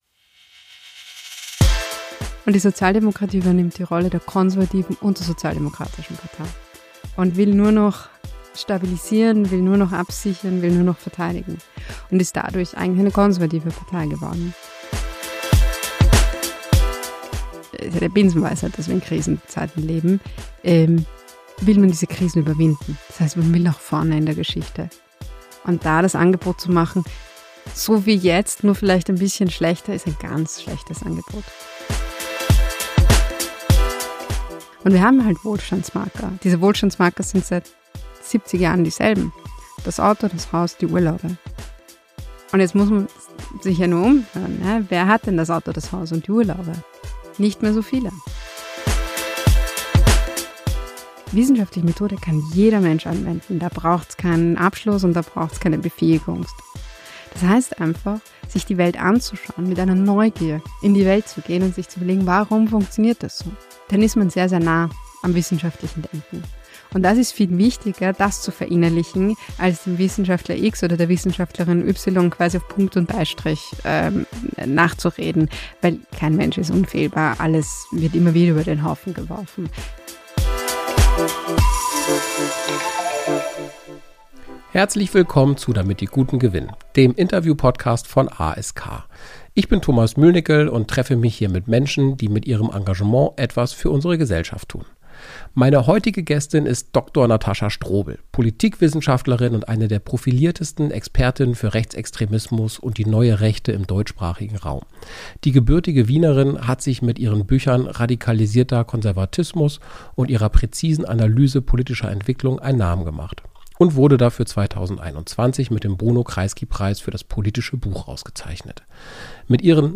Ein Gespräch über Mut, Klarheit und die Verteidigung unserer Demokratie.